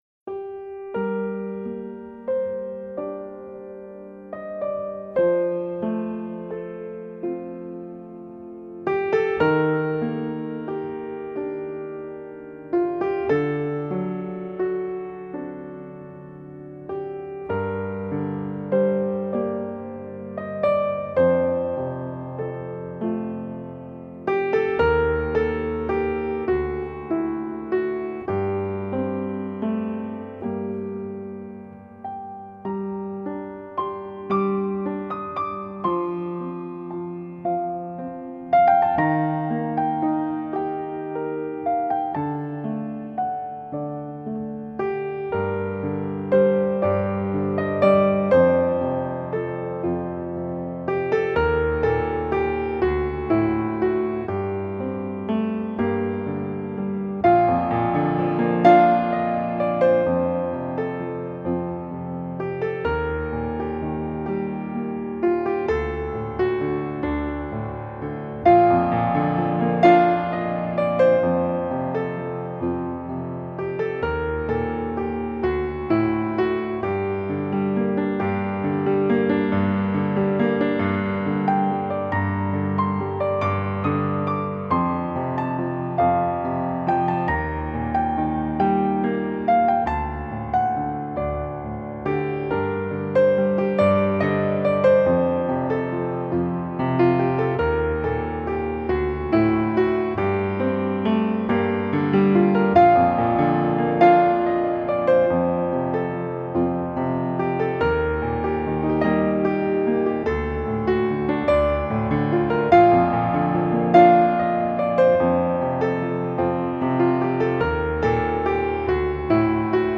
solo piano stylings
have a rich neoclassical tone
Simple melodic lines and easy pacing